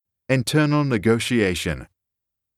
Internal Negotiation [in-tur-nl] [ni-goh-shee-ey-shuh n]